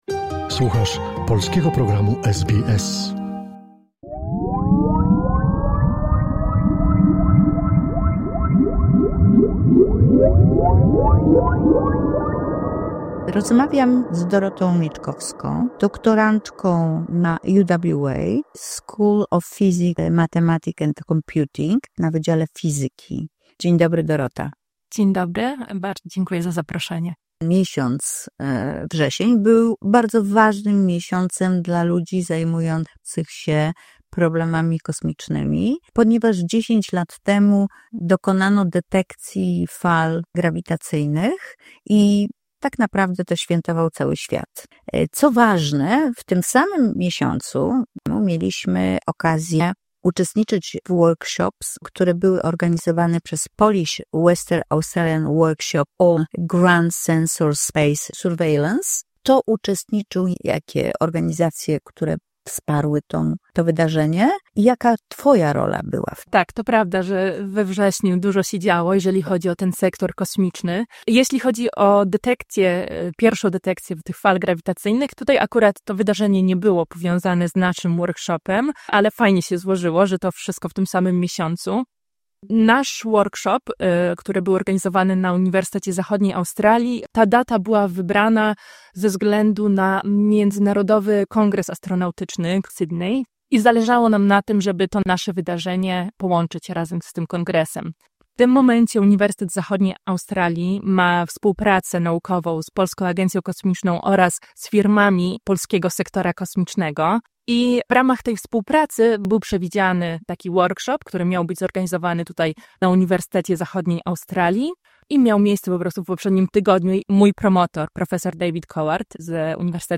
SBS po polsku